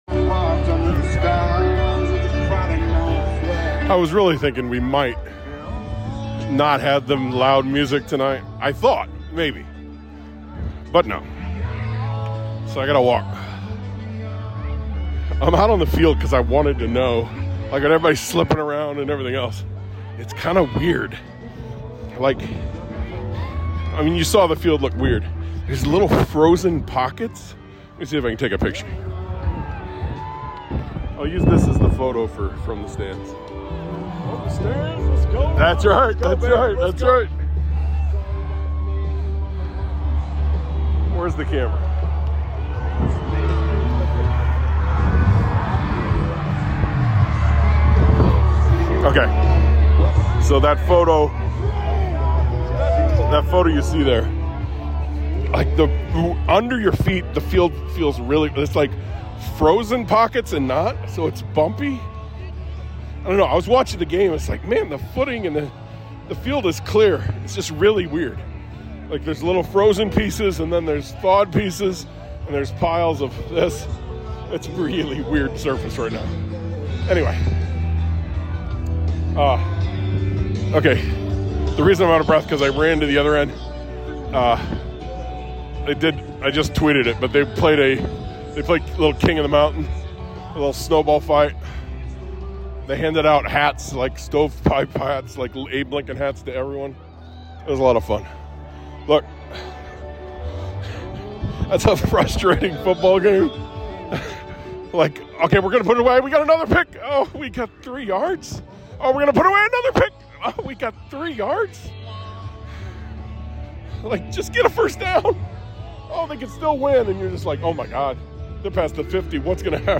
This FTS is From The Field. The very cold, slippery, snowy, awesome field. 8-4, baby.